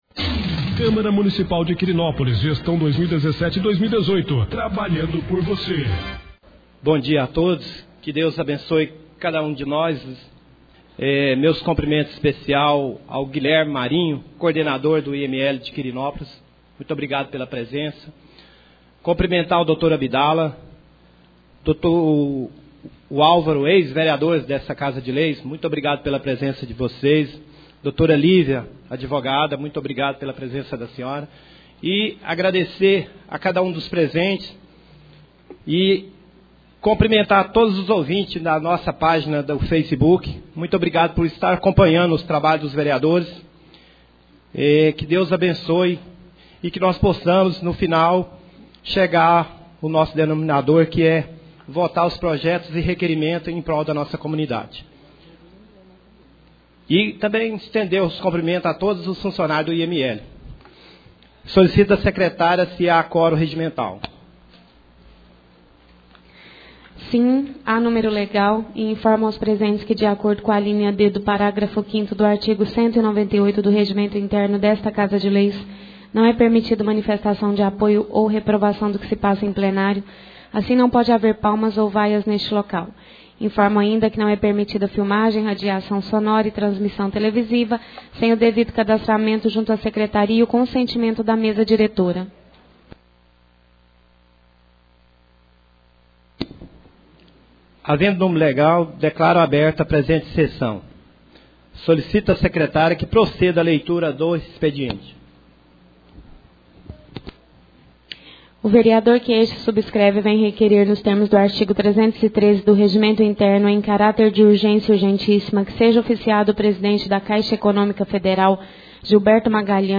5° Sessão Ordinária do Mês de Setembro 2017.